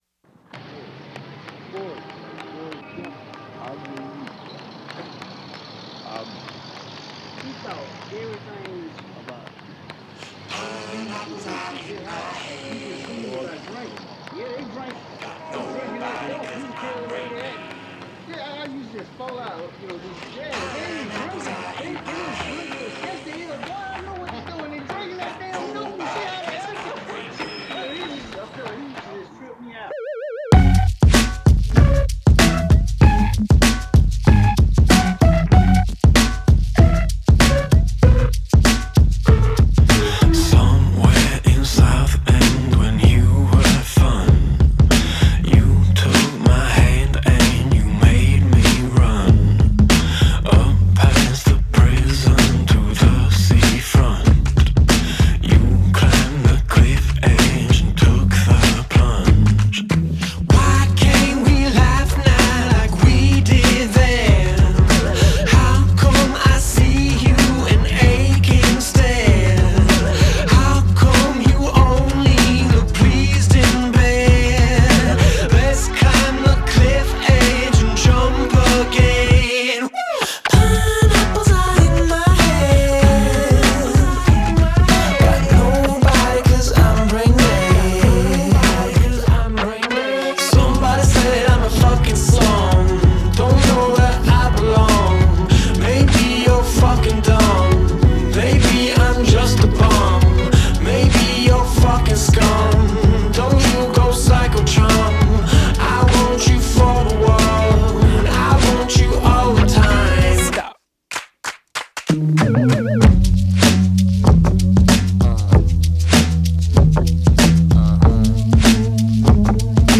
an utter success in the alternative world